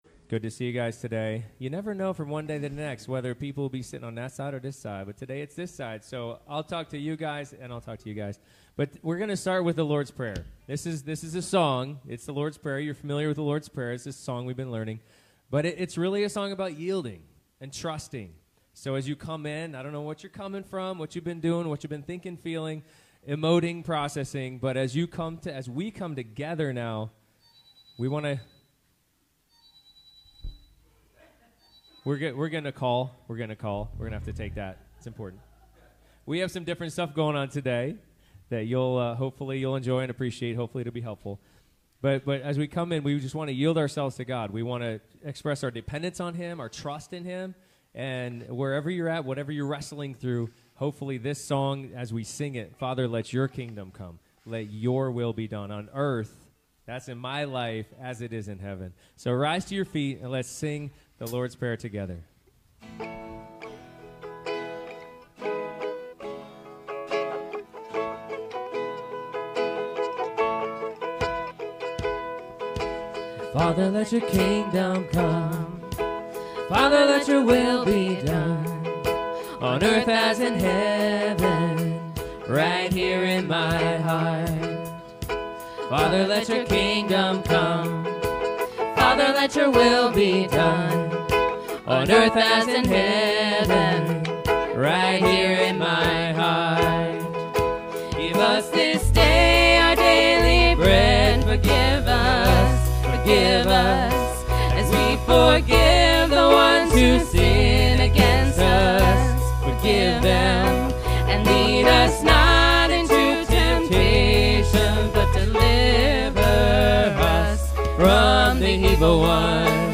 relation Passage: Ephesians 5:1-14 Service Type: Sunday Morning « How Should I be “Preaching the Gospel”?